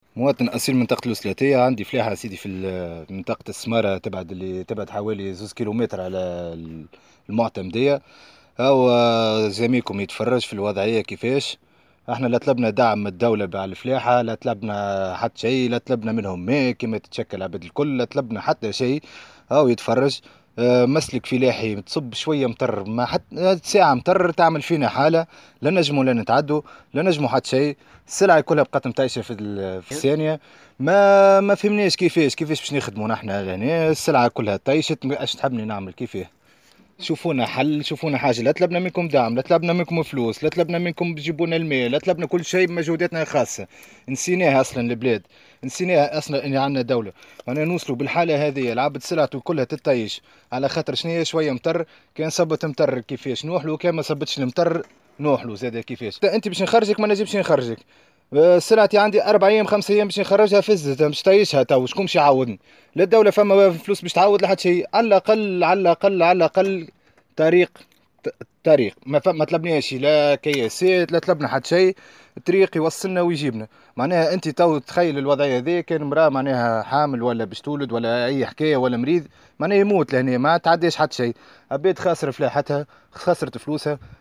وأفاد أحد الفلاحين في تصريح لمراسل الجوهرة اف ام، أن الأمطار أدت إلى تعطل الحركة بسبب رداءة المسالك الفلاحية التي تربطهم بالطريق الرئيسية المؤدية إلى مدينة الوسلاتية، إلى جانب إتلاف محاصيلهم الزراعية.